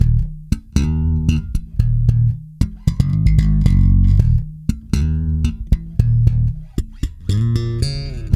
-JP THUMB.D#.wav